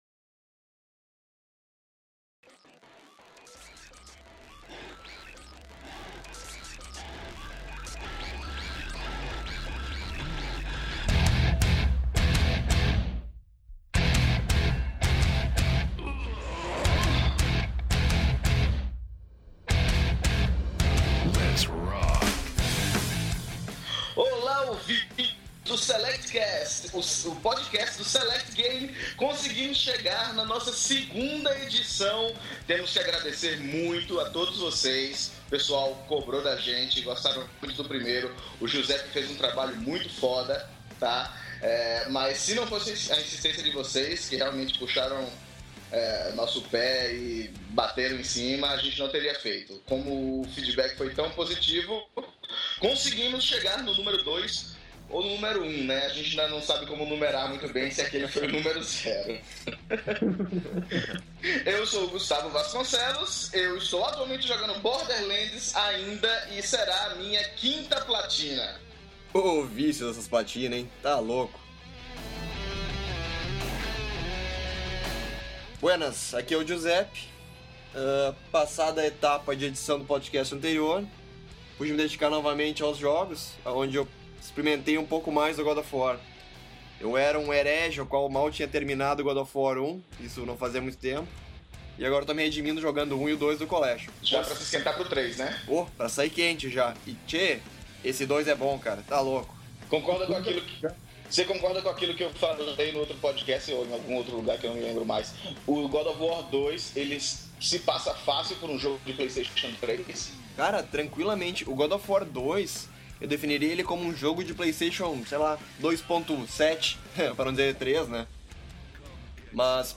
Desta vez nos certificamos de não causar surdez em ninguém, pois a verba do site para ações civis é limitada.